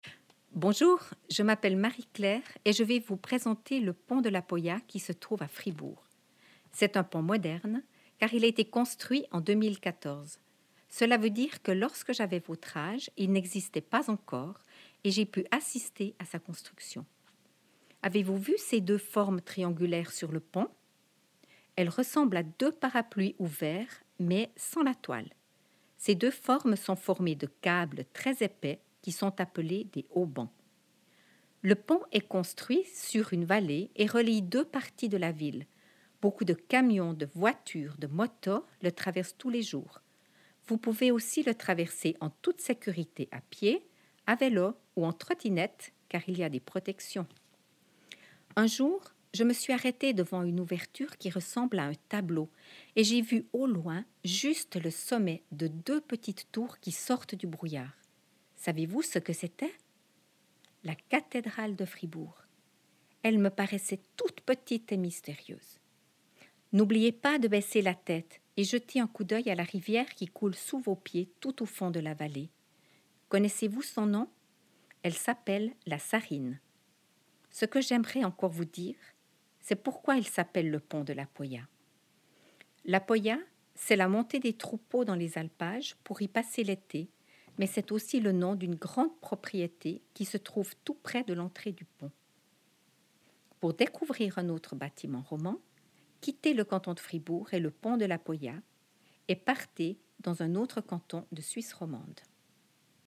AUDIOGUIDE LE PONT DE LA POYA (RNS2)